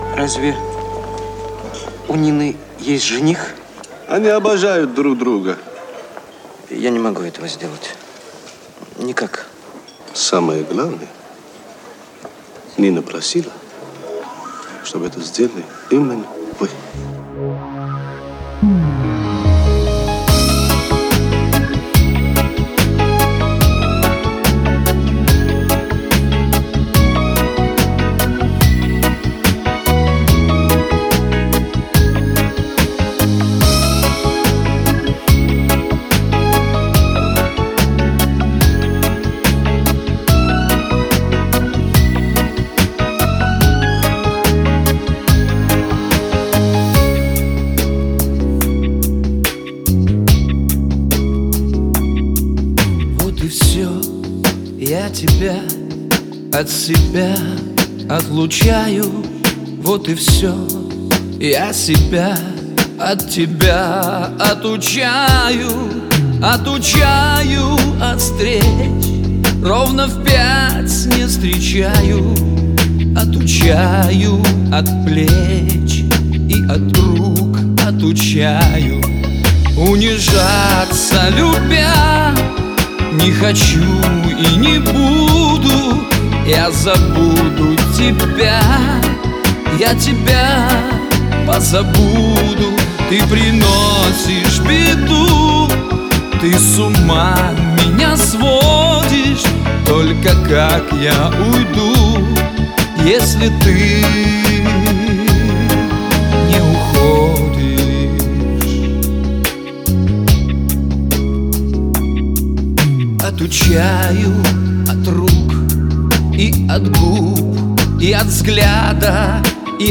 вокал, гитара
альт-саксофон, клавишные
ударные, перкуссия